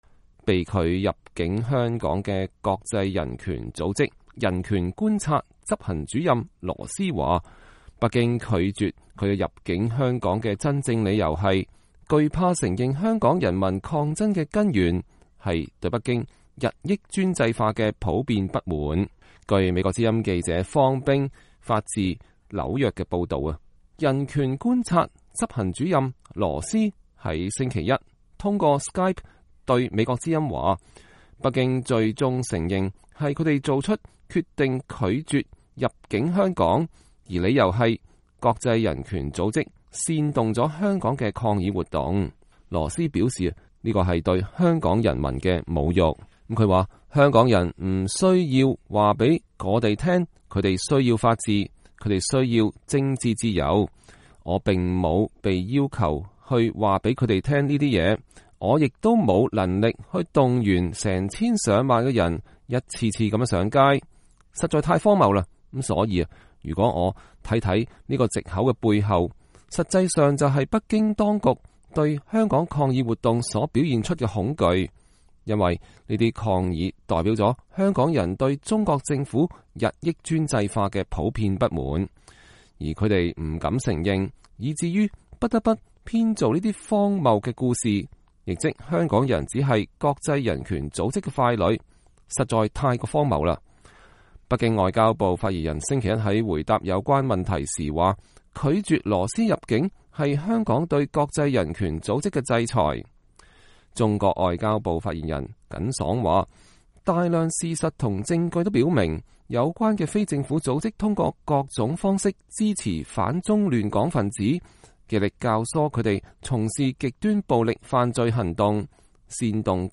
人權觀察執行主任羅斯週一(1月13日)通過Skype告訴美國之音，北京最終承認是他們做出決定拒絕其入境香港，而理由是國際人權組織煽動了香港的抗議活動。